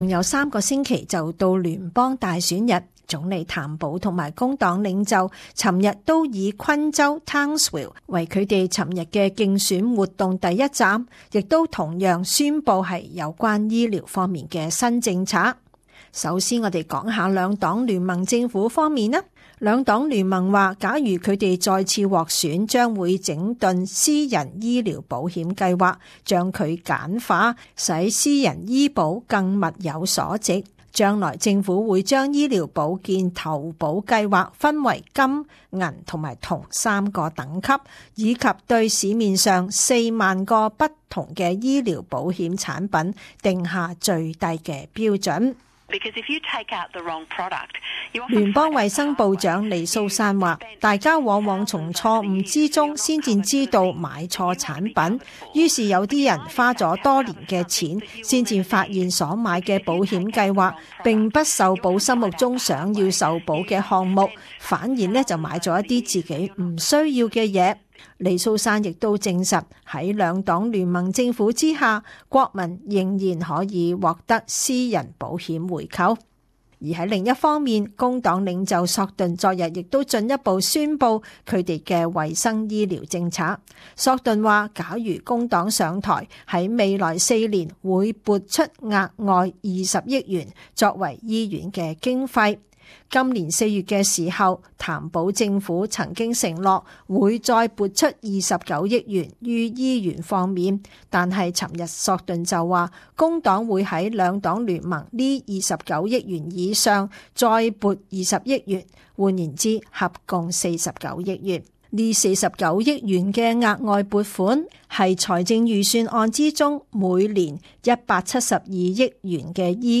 时事报导：朝野两党再在医疗政策上各出奇招